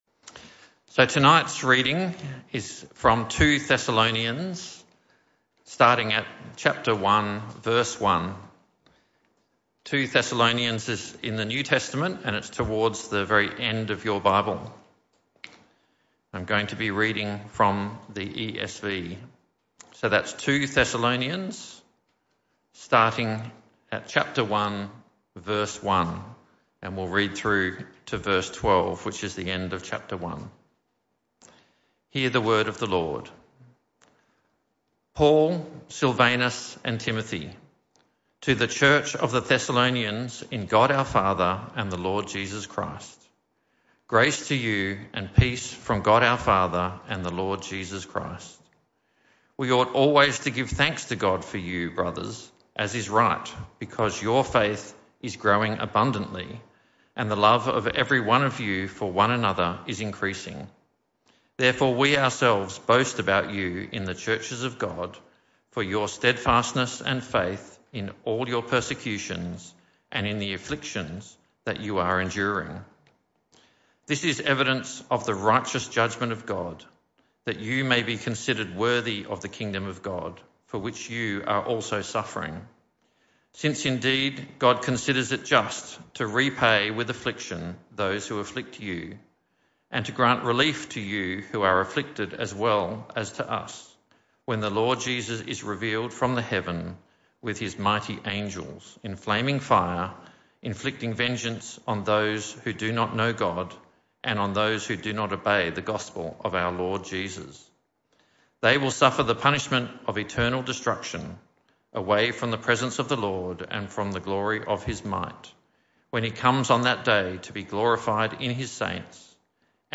This talk was part of the PM Service series entitled 1 & 2 Thessalonians.
Service Type: Evening Service